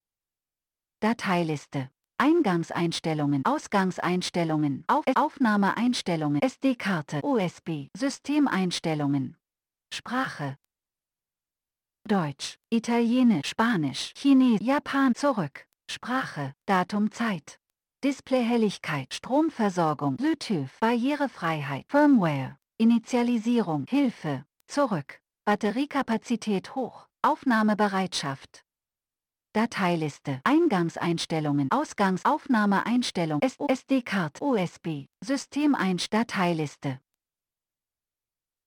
Attached is an audio quickly demonstrating how the German voice sounds.